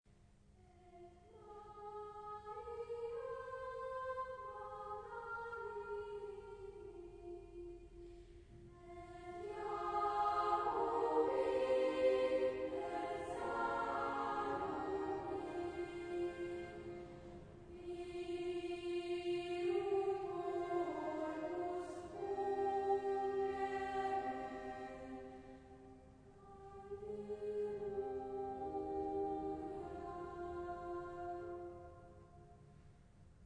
filii et filiae, dans un lointain a capella d’un choeur d’anges descendant de la voûte sombre d’une nef plongée dans les ténèbres.